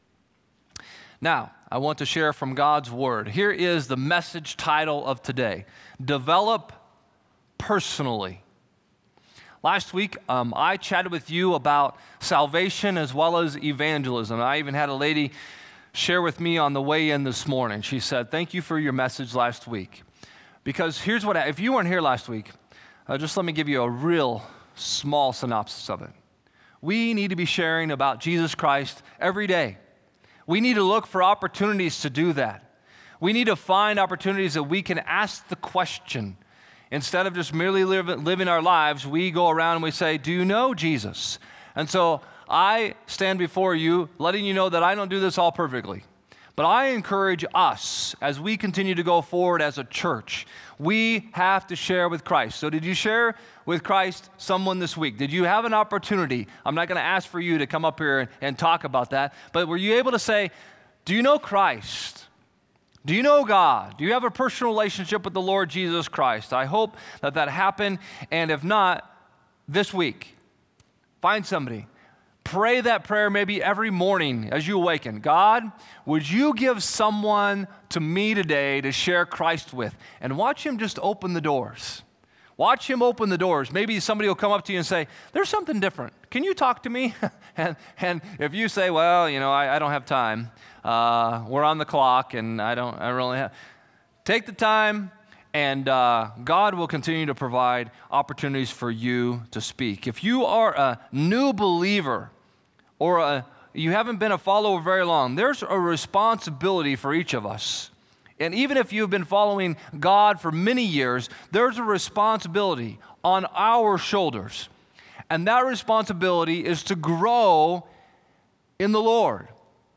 Click Here to Follow Along with the Sermon on the YouVersion Bible App Romans 1:26-27 English Standard Version 26 For this reason God gave them up to dishonorable passions.